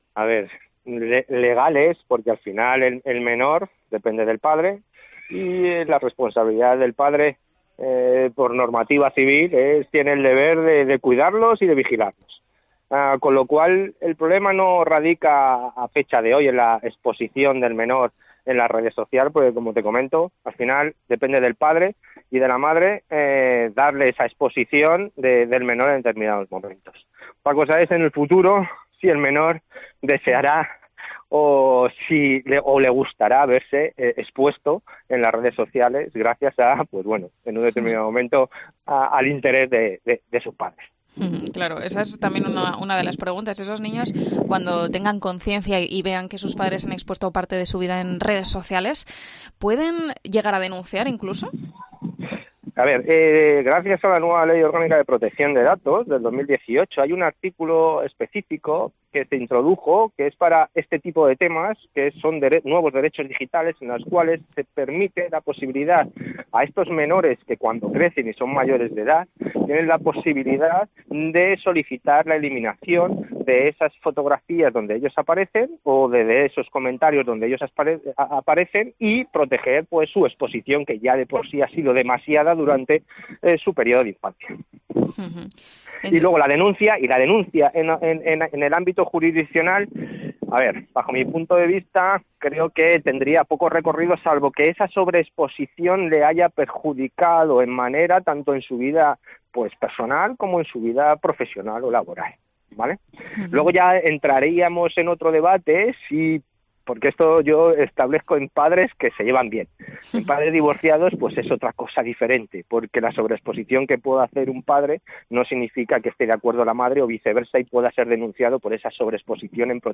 abogado especializado en Nuevas Tecnologías sobre la exposición de menores en Internet